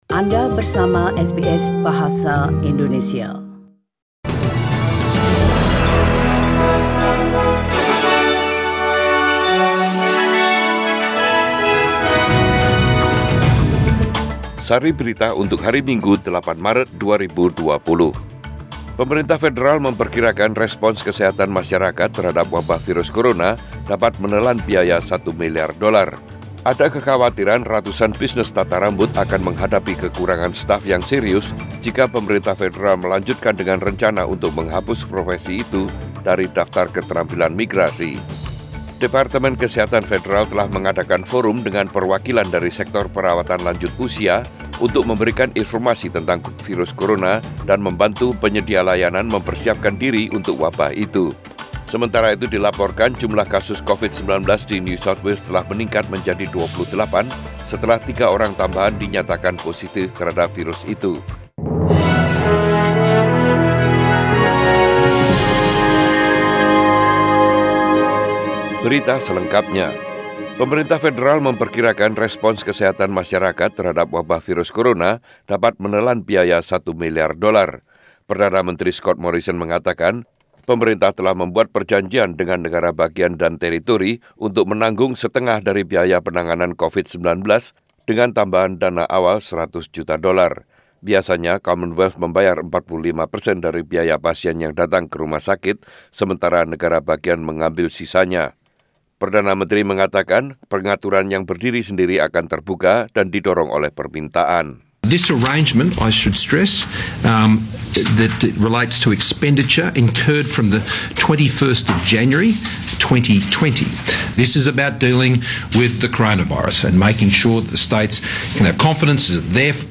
SBS Radio News in Indonesian - 8 March 2020